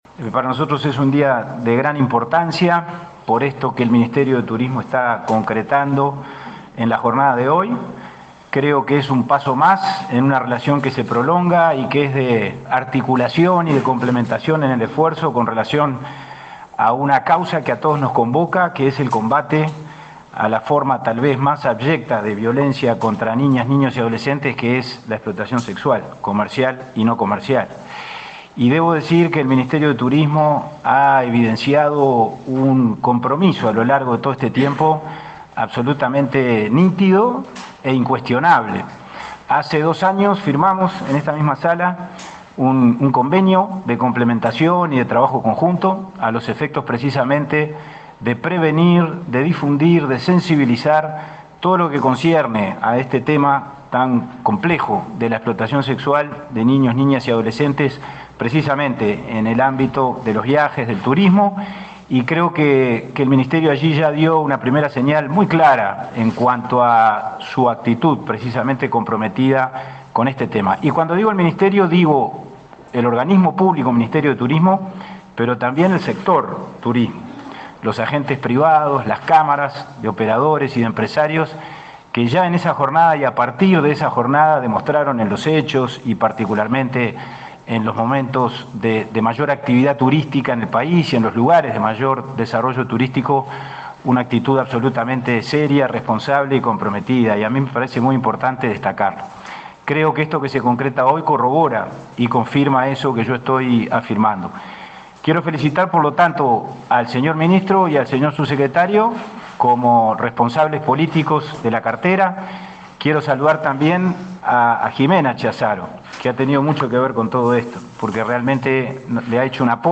Palabras de autoridades en el Ministerio de Turismo
Este lunes 25, en Montevideo, el presidente del Instituto del Niño y el Adolescente del Uruguay, Pablo Abdala, y el ministro de Turismo, Tabaré Viera,